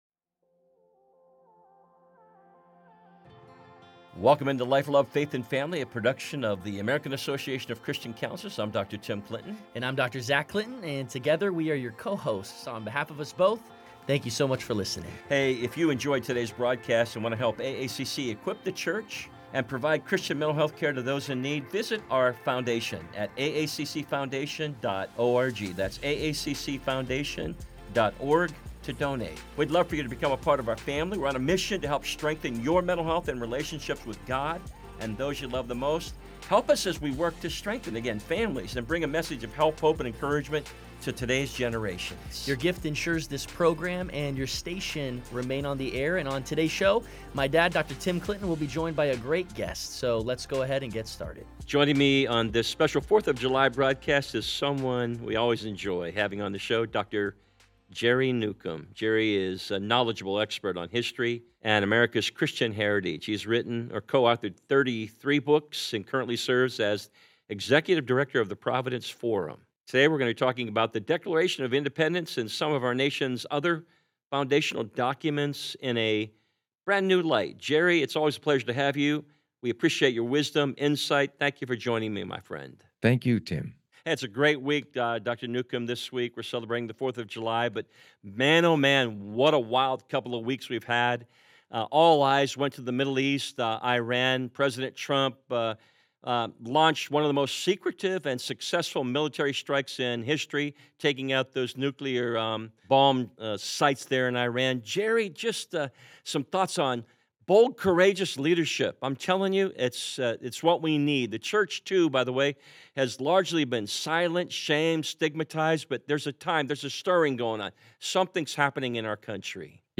On this special Fourth of July broadcast